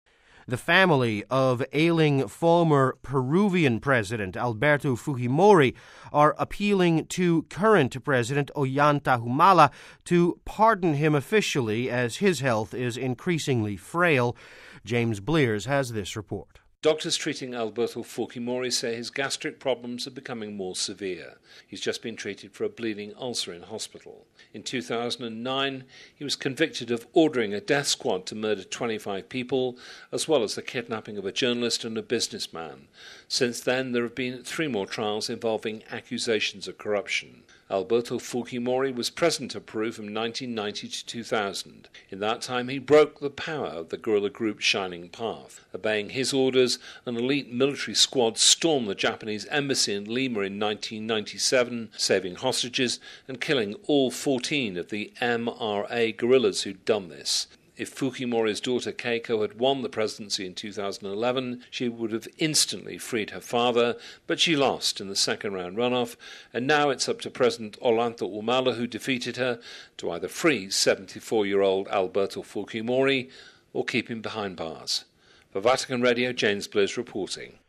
(Vatican Radio) The Family of ailing former Peruvian President Alberto Fujimori, are appealing to current President Ollanta Humala to officially pardon him, as his health is increasingly frail. Doctors treating Alberto Fujimori say his gastric problems are becoming more severe.